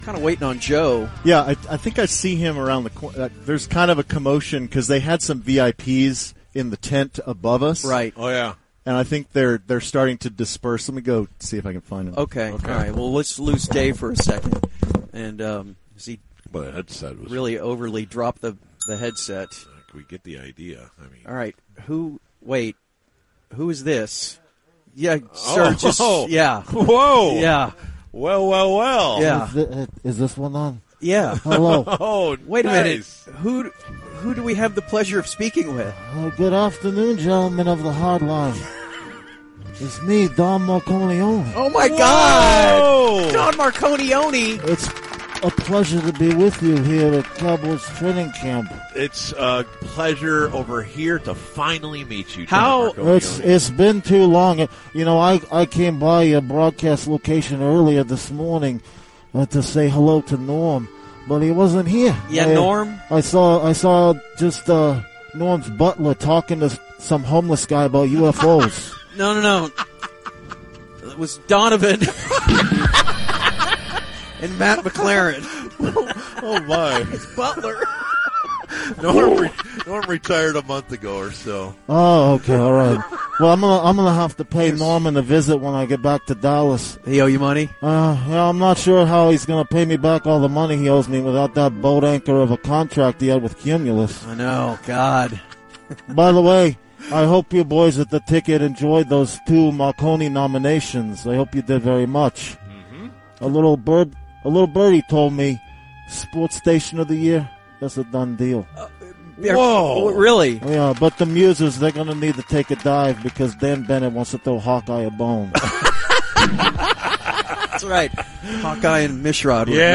drops by the Ticket tent at Cowboys Training Camp 2023.